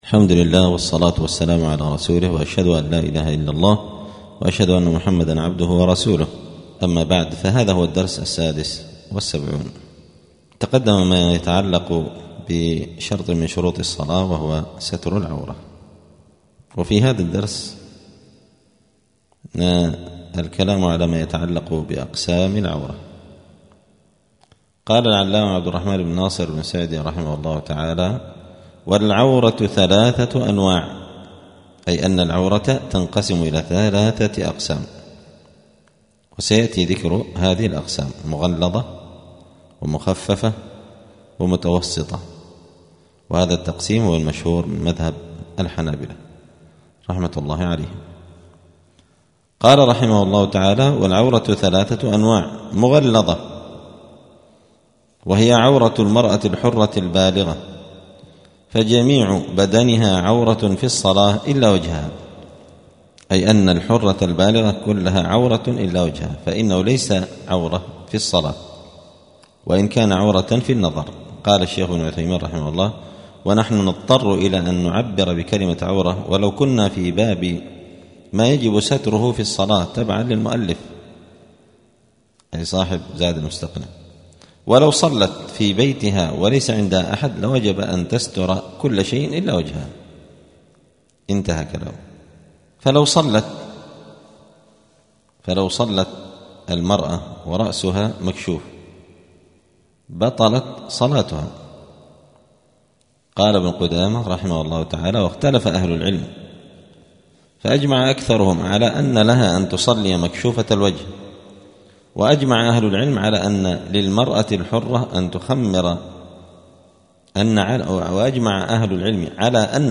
*الدرس السادس والسبعون (76) {كتاب الصلاة باب شروط الصلاة أقسام العورة}*